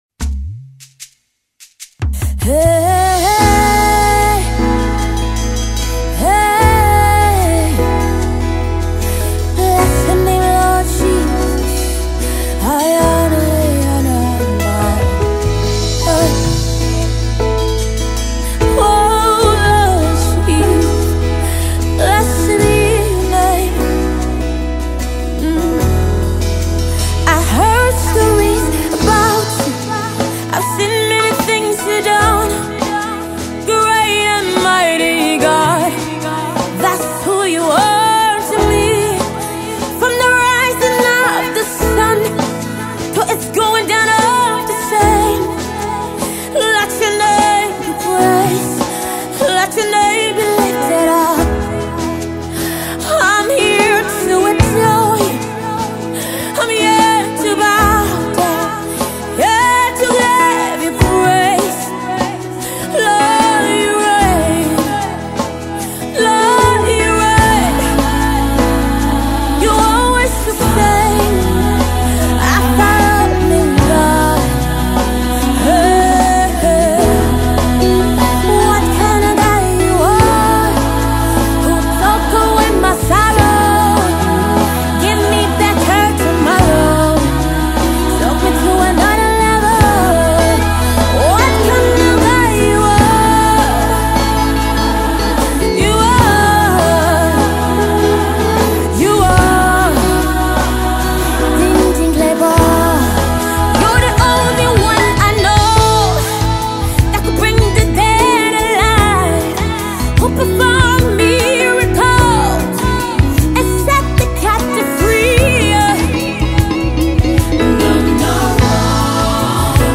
Gosple
with those killing vocals